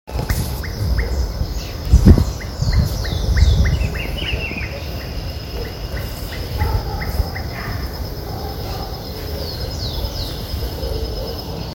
Leptodactylus gracilis
Class: Amphibia
Location or protected area: Concordia
Condition: Wild
Certainty: Recorded vocal